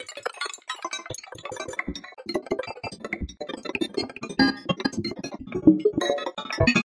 描述：快速和混乱的声音元素序列，取自金属音源，停留在一个相当高的范围。
标签： 金属 拨浪鼓 敷于
声道立体声